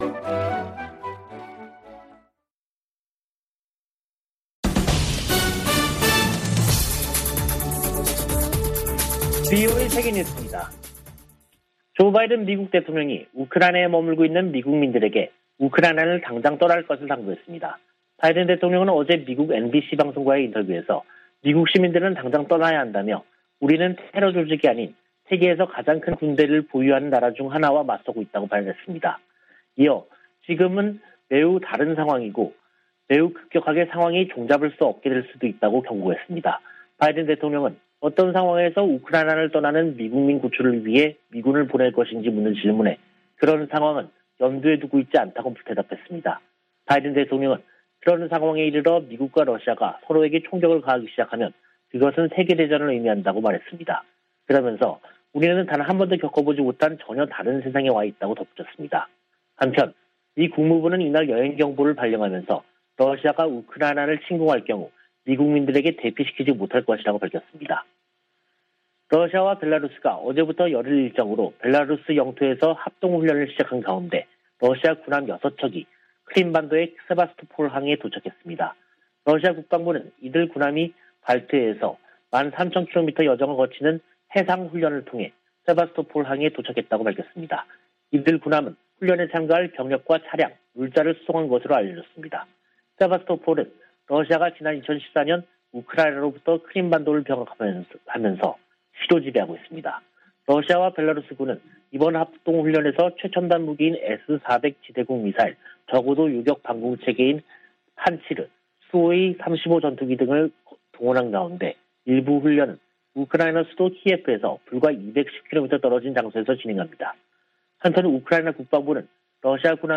VOA 한국어 간판 뉴스 프로그램 '뉴스 투데이', 2022년 2월 11일 3부 방송입니다. 토니 블링컨 미 국무부 장관은 4개국 안보협의체 '쿼드(Quad)'가 공통 도전에 대응하고 번영 기회를 찾는 것이 목표라고 밝혔습니다. 미국은 북한의 잘못된 행동을 막기 위해 유엔 회원국들이 한 목소리를 내야 한다고 밝혔습니다.